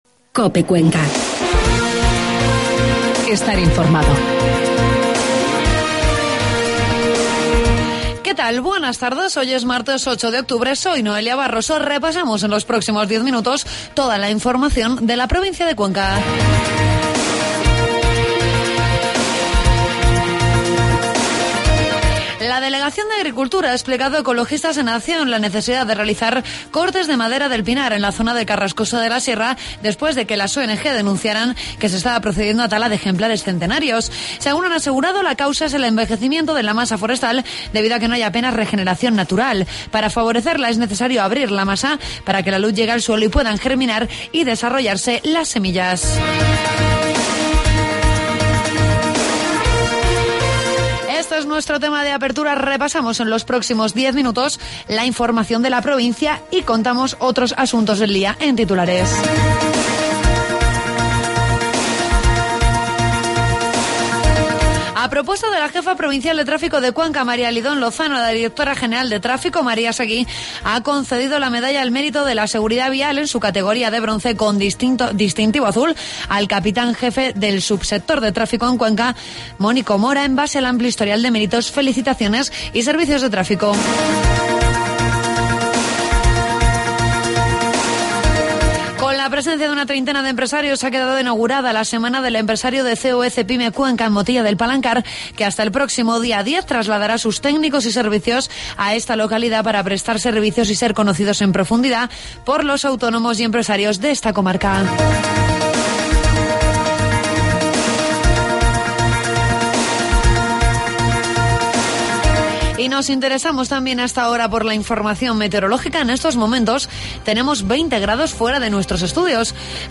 AUDIO: Toda la información de la provincia de Cuenca en los informativos de mediodía de COPE